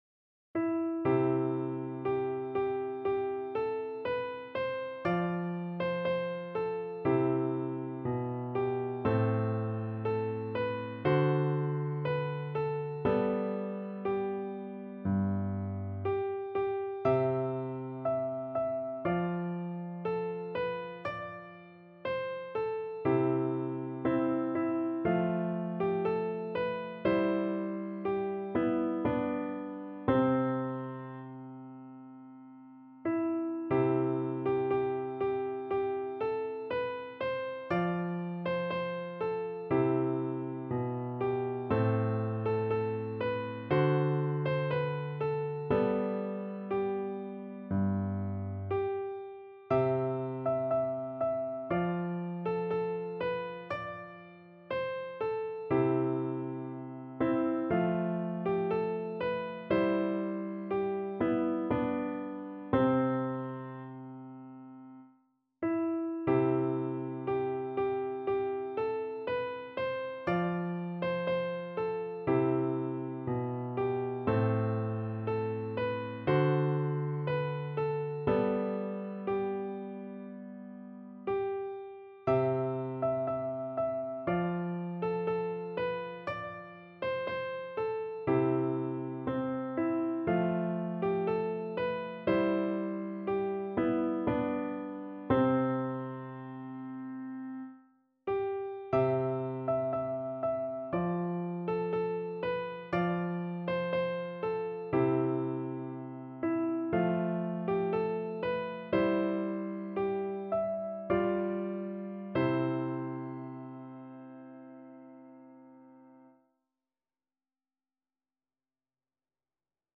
No parts available for this pieces as it is for solo piano.
4/4 (View more 4/4 Music)
~ = 100 Adagio
Piano  (View more Easy Piano Music)
Classical (View more Classical Piano Music)